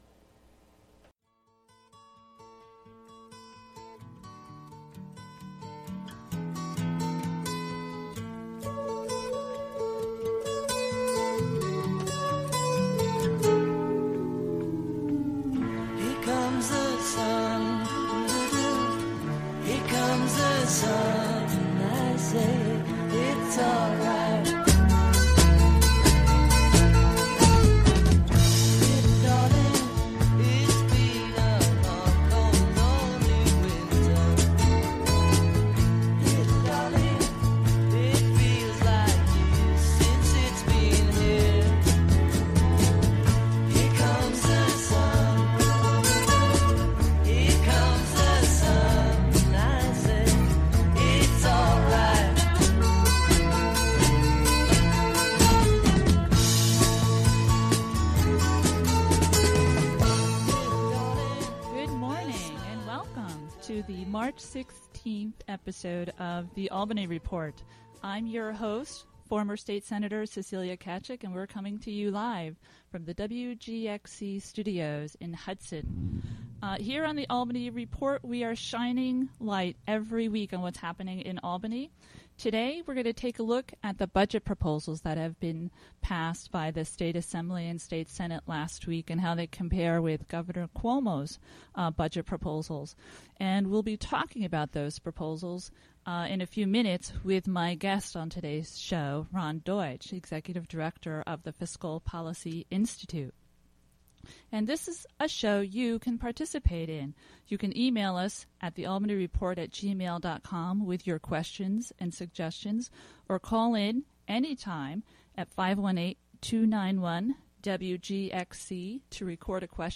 2015 shows The Albany Report Hosted by Cecilia Tkaczyk. broadcasts The Albany Report